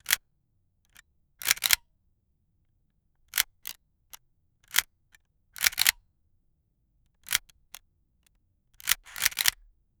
Camera Shutter Click
camera-shutter-click-igdfub2r.wav